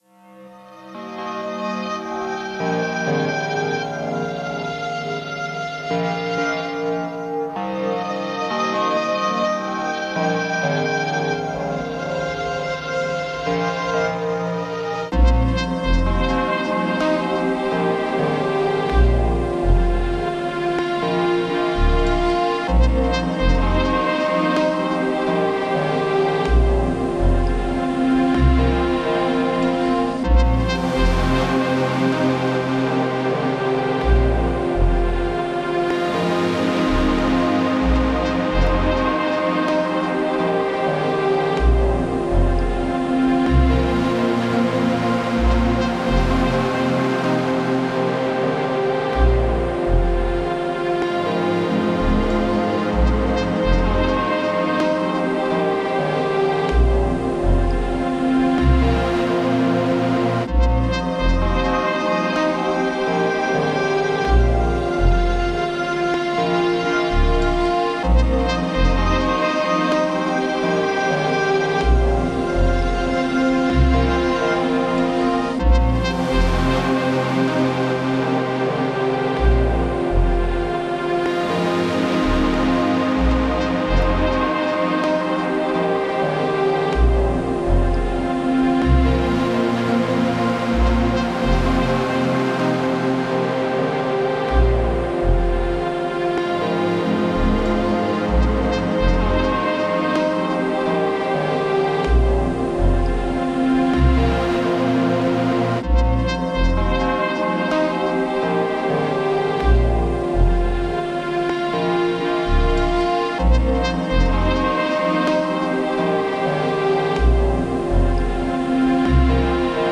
une musique d’horreur angoissante
une musique d’horreur libre de droit, sombre et immersive.